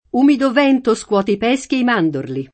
pesco [ p $S ko ] s. m. («albero»); pl. peschi